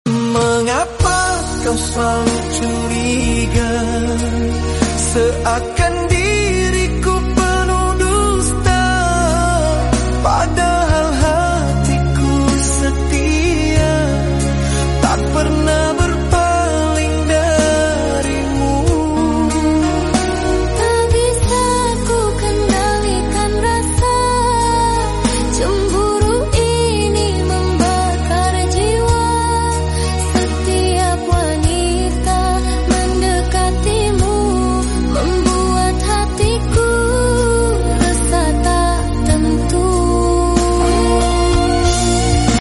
slow Rock